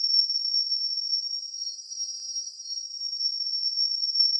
Brakes.wav